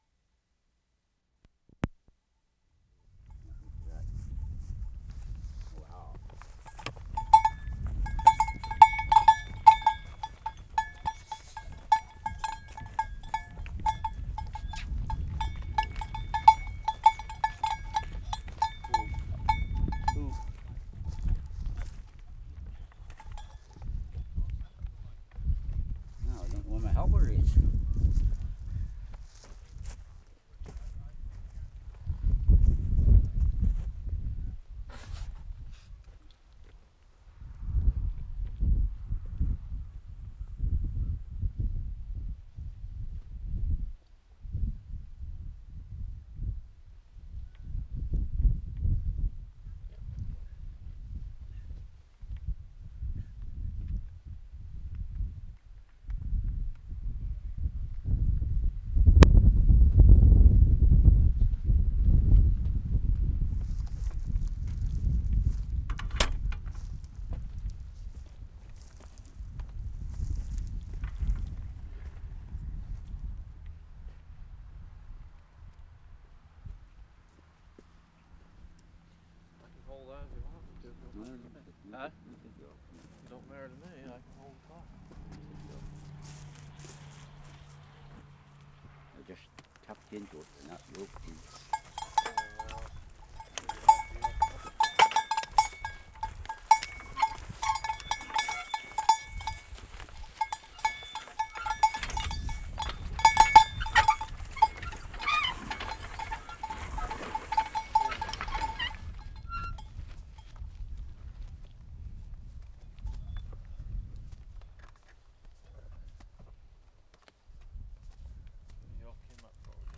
ROSS FARM, NOVA SCOTIA Oct. 13, 1973
HARNESSING OXEN TO PLOW 2'55"
21. Lots of wind on microphone. Two men are harnessing oxen (1'20"). Bell clanking throughout.